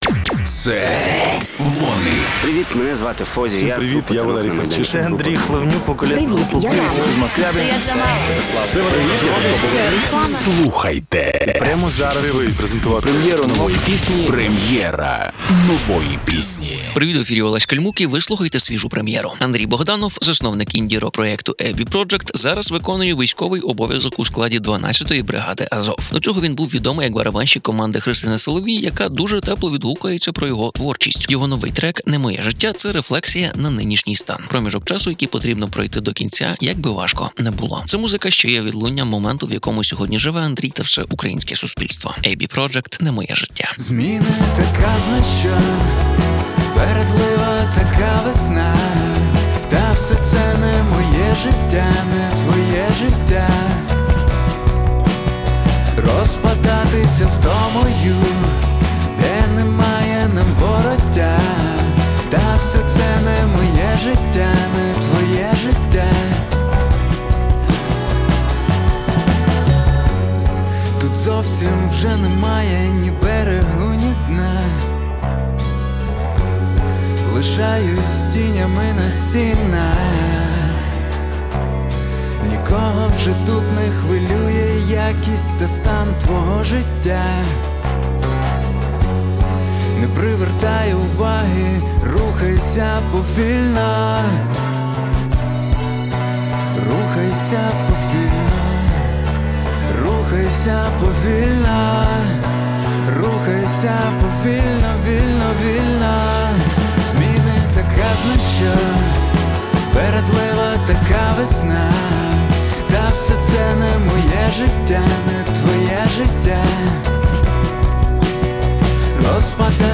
інді-рок